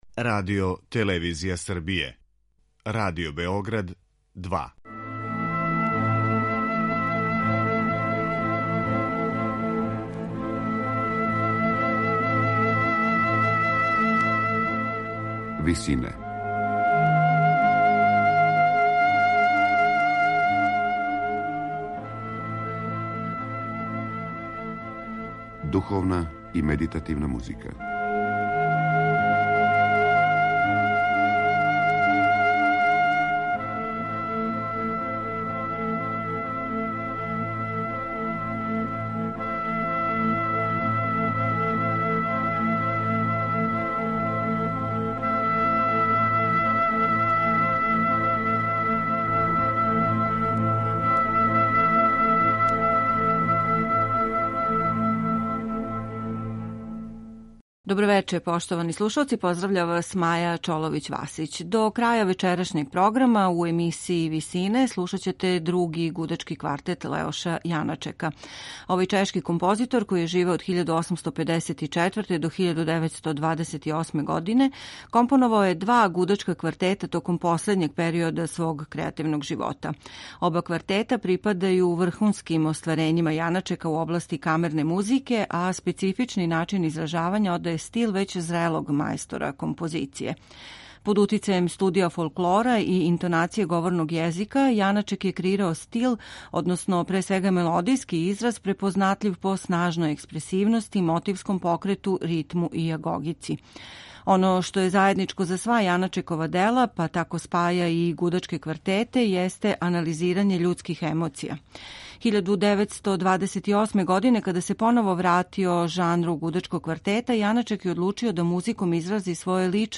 Гудачки квартет бр. 2 чешког композитора Леоша Јаначека